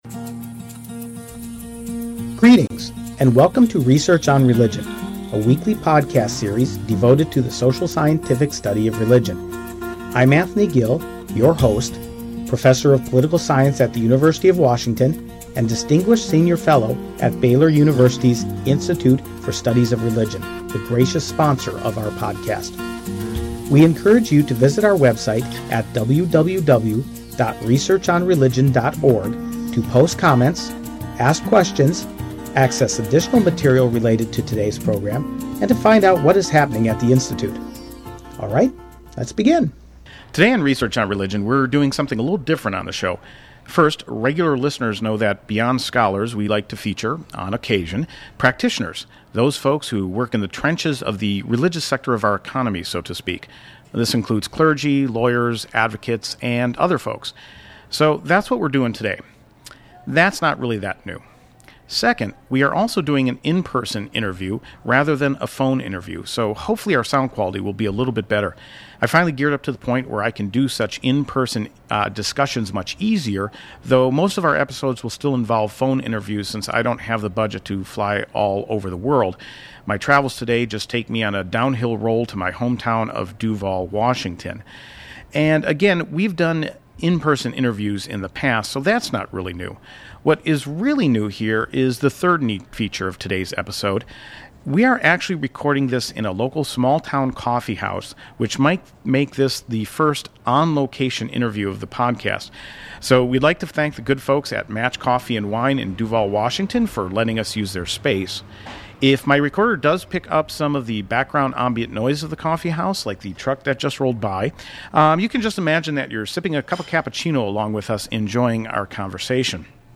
I tend to be a “nuts and bolts” kind of person, wanting to know the step-by-step methods in which something is done and this interview covers an entire “construction project” of building a church from start to finish in a very compressed period of time.